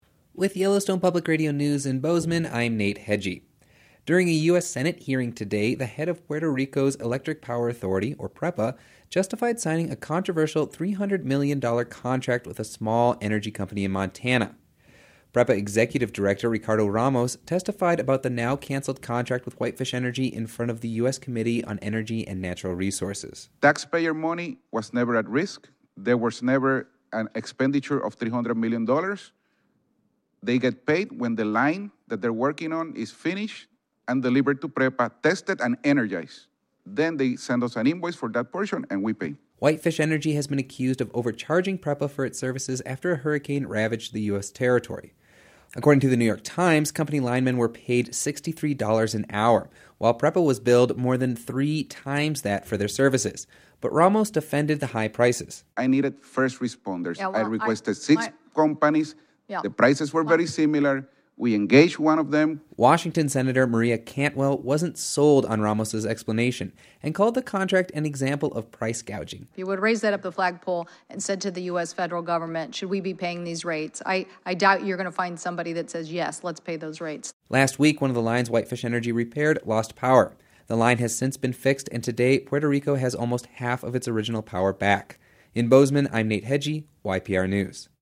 PREPA executive director Ricardo Ramos testified about the now-cancelled contract with Whitefish Energy in front of the U.S. Committee on Energy and Natural Resources.
During a U.S. Senate hearing Tuesday, the head of Puerto Rico’s electric power authority, or PREPA, justified signing a controversial, $300 million contract with a small energy company in Montana.